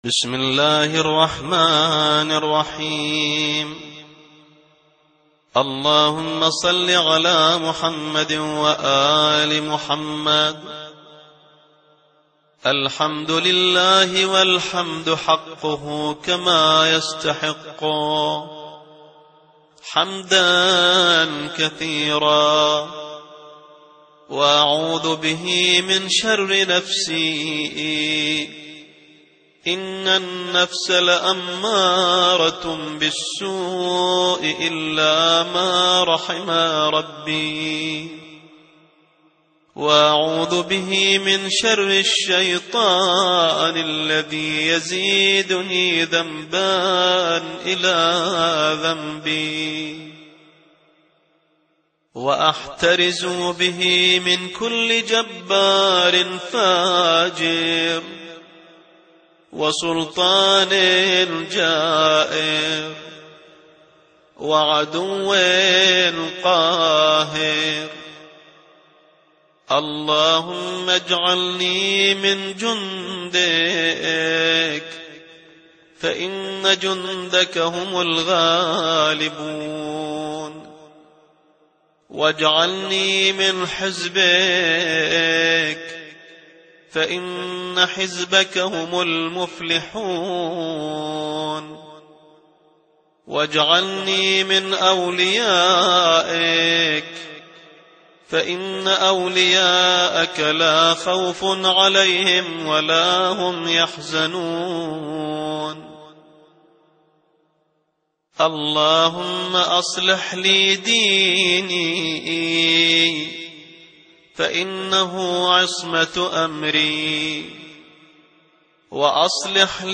دعاء يوم الثلاثاء مكتوبة